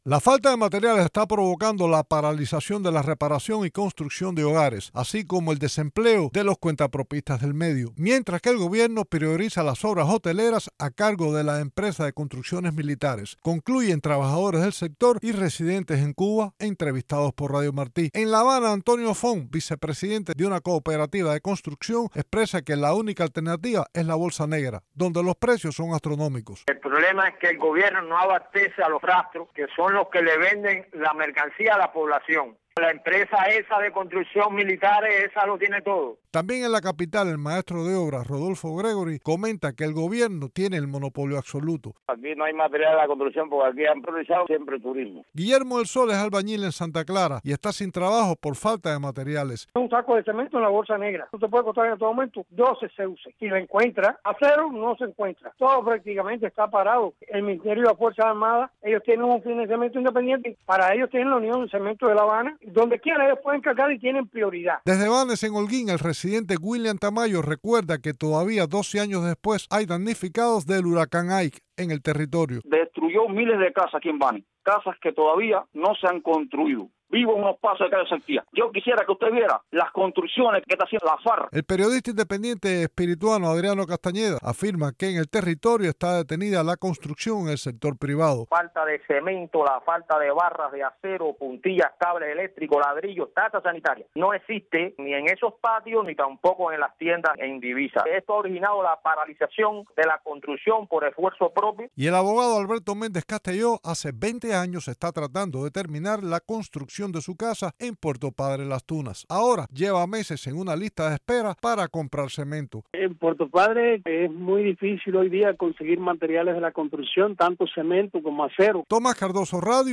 El gobierno prioriza los materiales para las obras de la empresa de construcciones militares, especialmente las de la industria hotelera de la corporación Gaviota, dijeron desde la isla varias personas entrevistadas por Radio Televisión Martí.